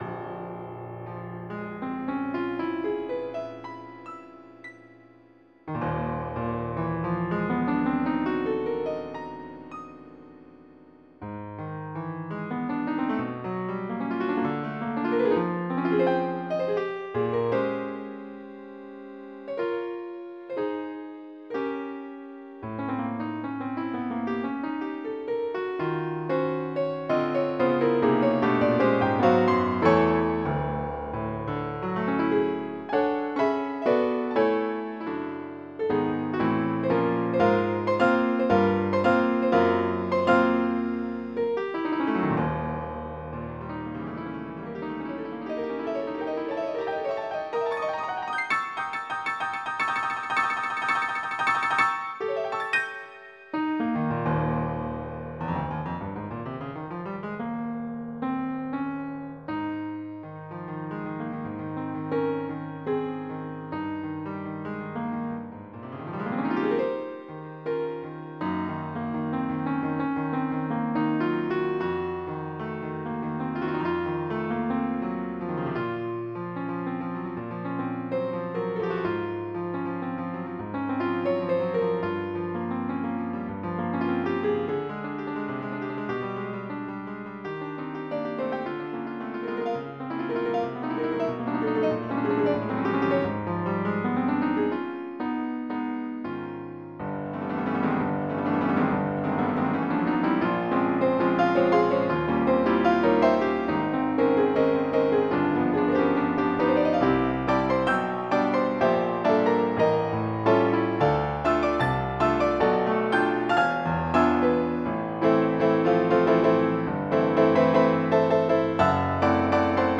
3-2 打ち込みによる演奏　 3
打ち込み.MIDをmp3に変換